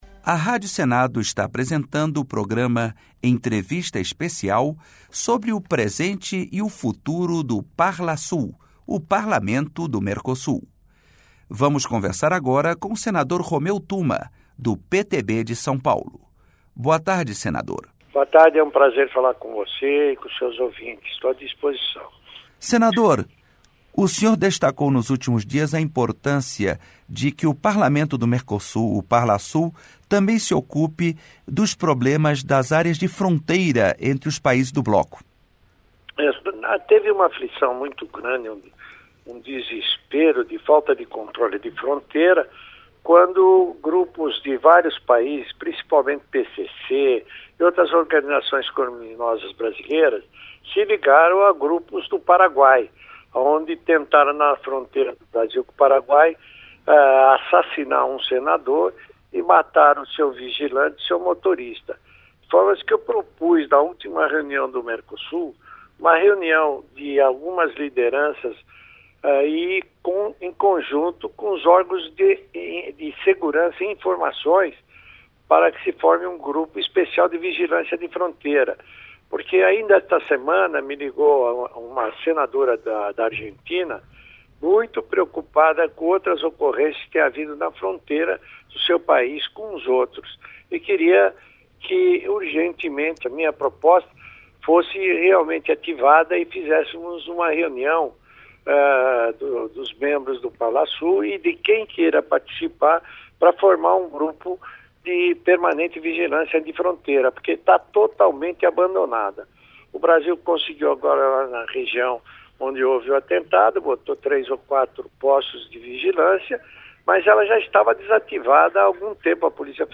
Entrevista com os senadores Inácio Arruda (PCdoB-CE) e Romeu Tuma (PTB-SP).